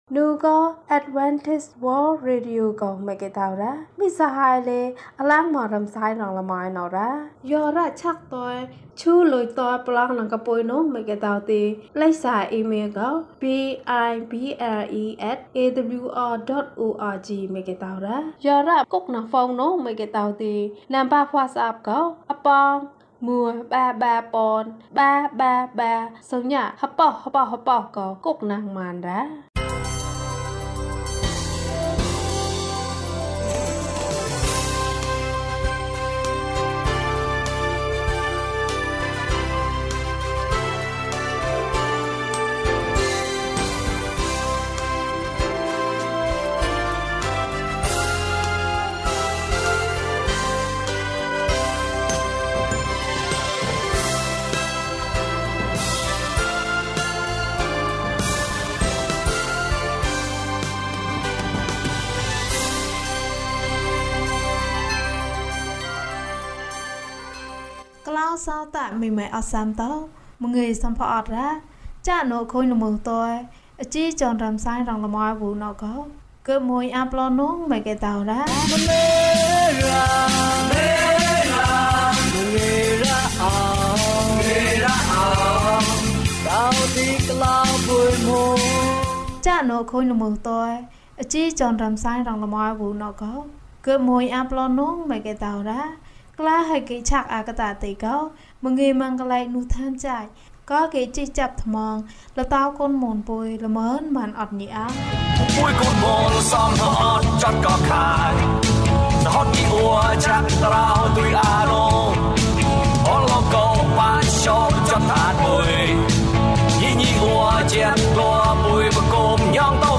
သခင်ယေရှုသည် ကျွန်ုပ်၏အပြစ်များကို ယူဆောင်သွားပါသည်။ ကျန်းမာခြင်းအကြောင်းအရာ။ ဓမ္မသီချင်း။ တရားဒေသနာ။